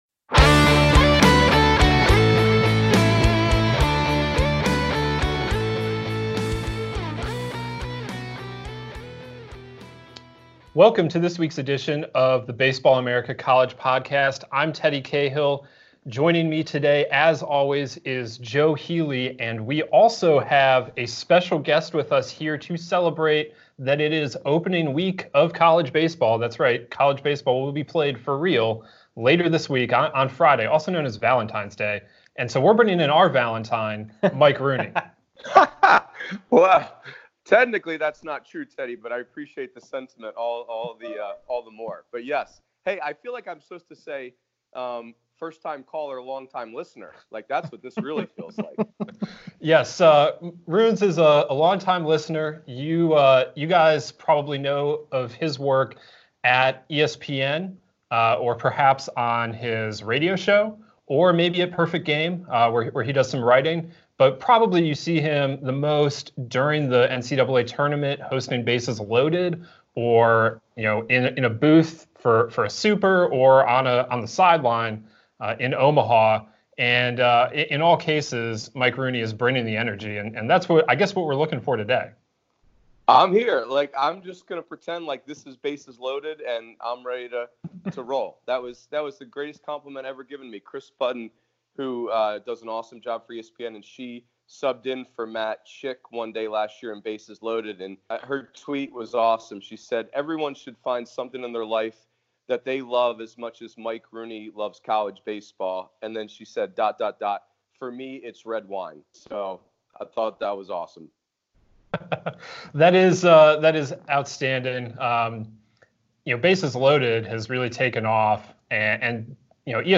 The trio spends the episode going through their respective predictions for the 2020 seasons, including national championship picks, Omaha sleepers, and national player and pitcher of the year.